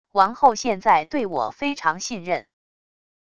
王后现在对我非常信任wav音频生成系统WAV Audio Player